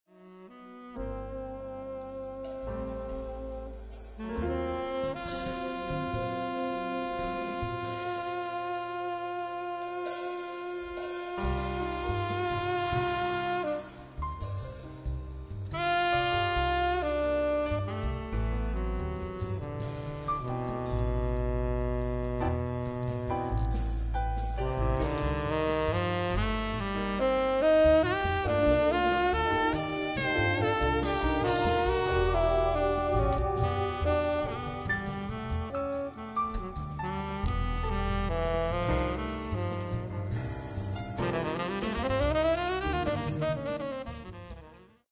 piano
sax
bass
drums
percussion